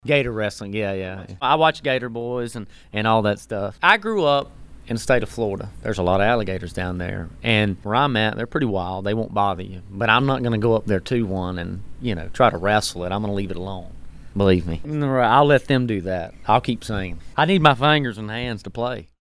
AUDIO: Easton Corbin admits he’s a fan of the television show, Gator Boys.